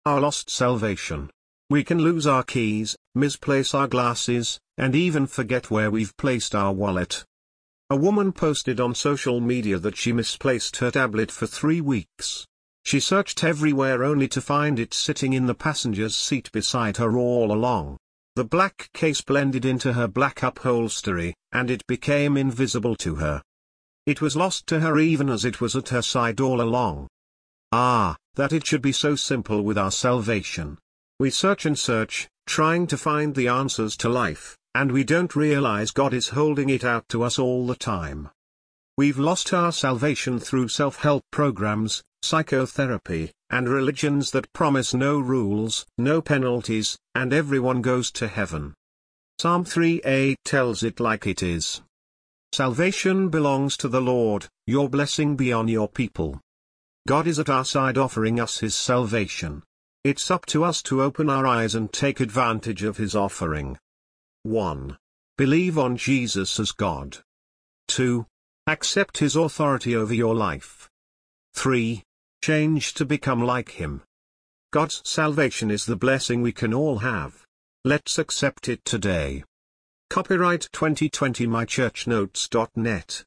English Audio Version